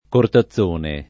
[ korta ZZ1 ne ]